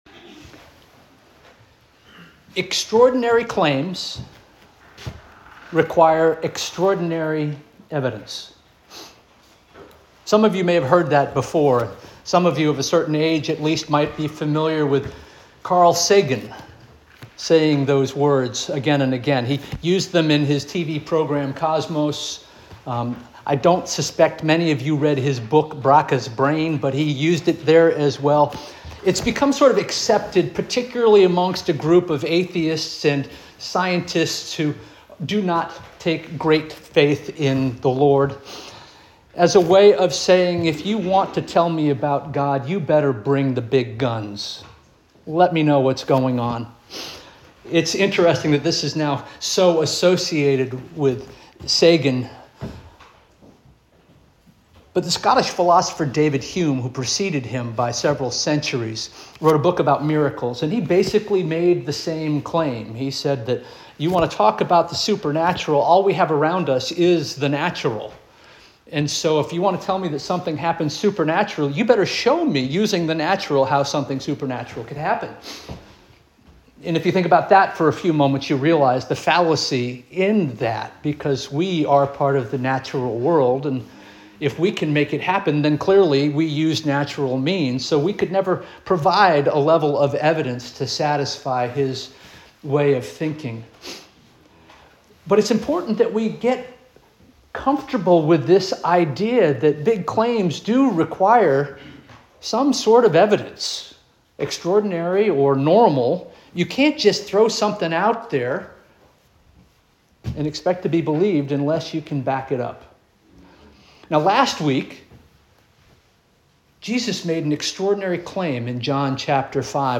November 23 2025 Sermon - First Union African Baptist Church